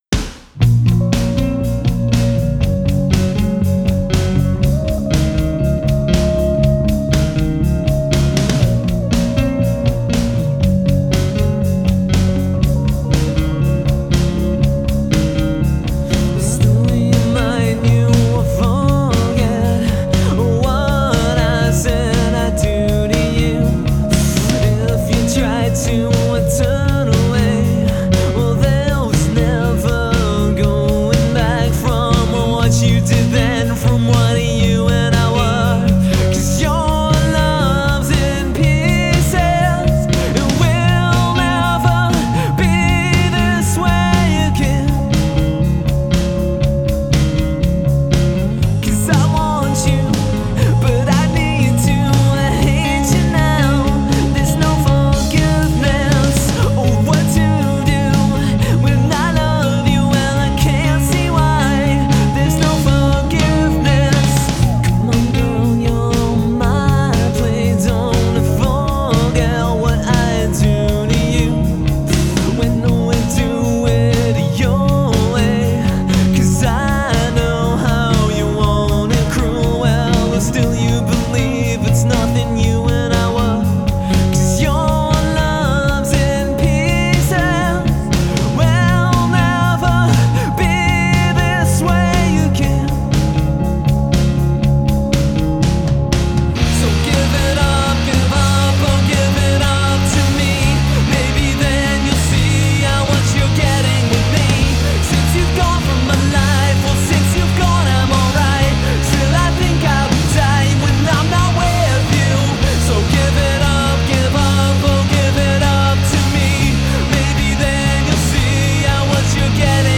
• Genre: Alternative / Indie